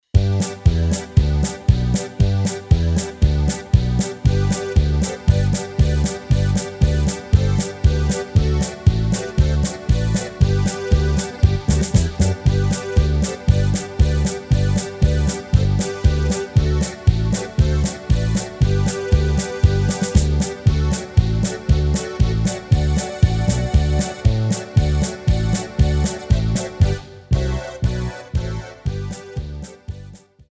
Rubrika: Národní, lidové, dechovka
- polka - směs